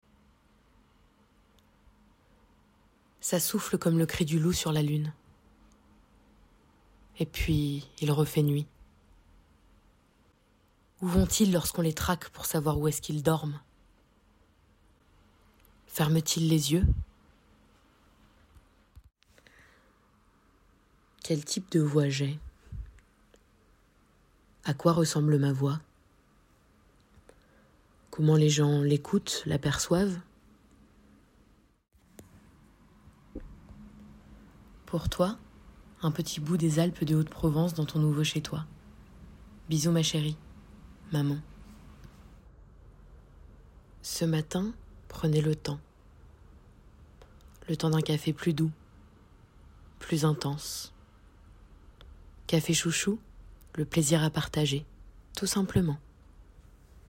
Voix off
Démo/échantillons de ma voix en quatre temps (débutante)
- Mezzo-soprano